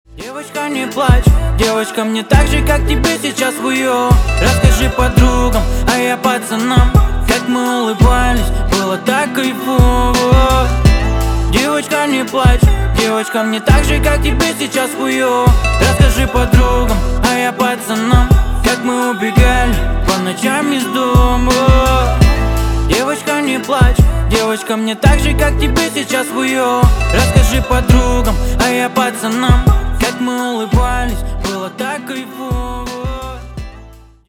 бесплатный рингтон в виде самого яркого фрагмента из песни
Рэп и Хип Хоп
грустные